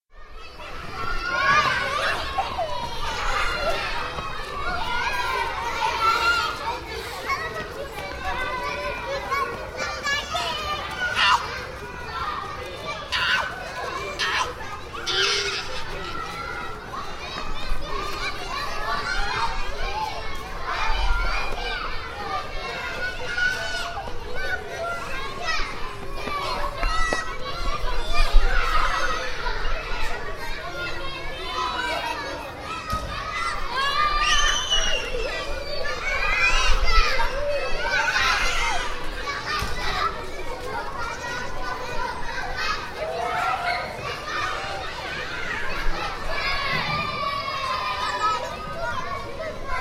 Enfants
enfants.mp3